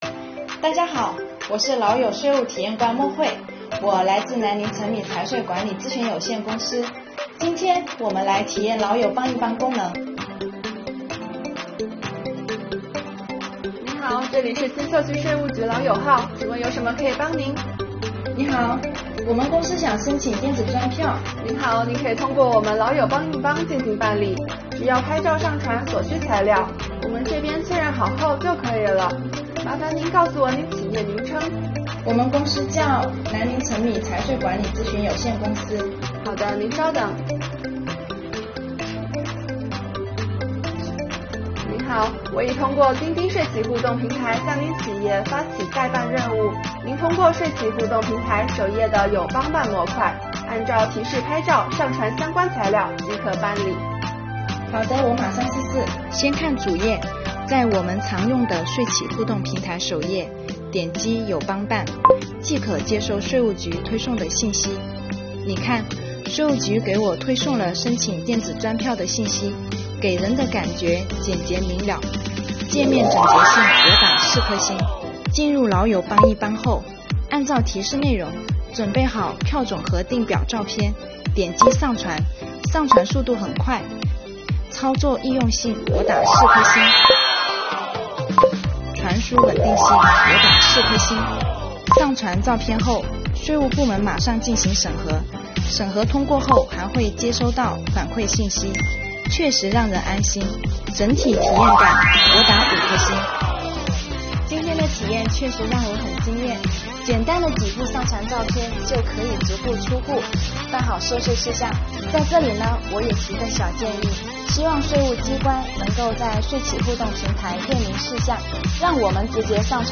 最后，来听听体验官怎么说